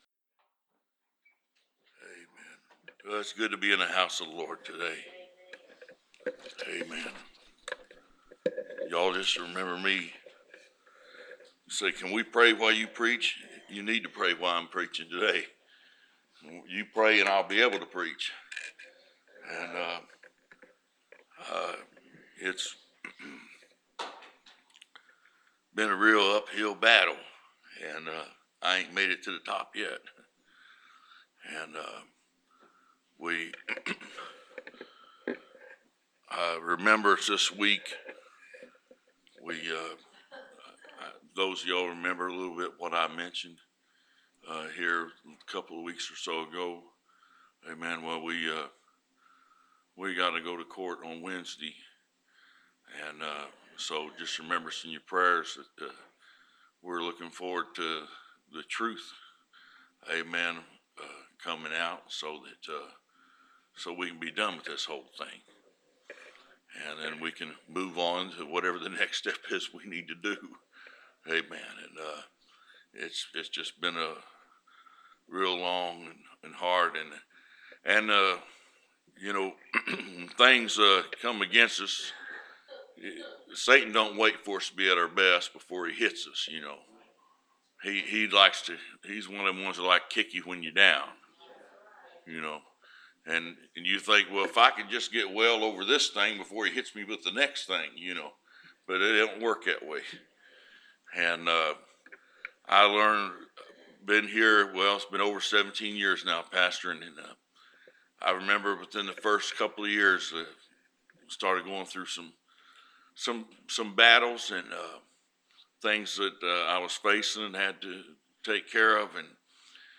Preached May 29, 2016